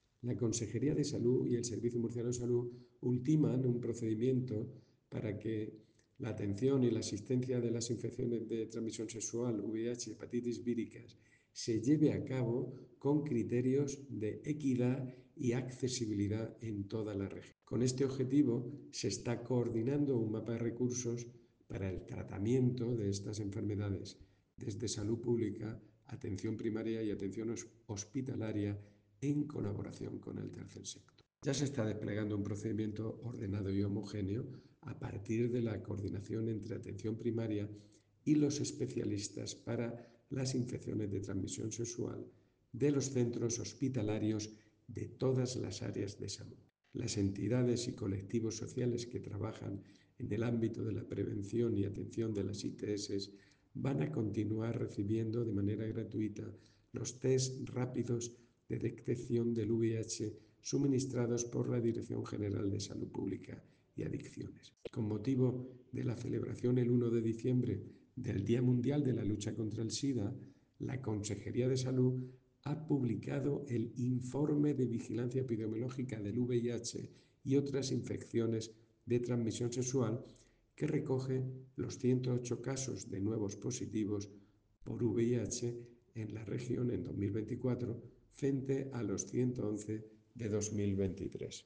Sonido/ Declaraciones del director general de Salud Pública, José Jesús Guillén, con motivo de la conmemoración del Día Mundial del Sida.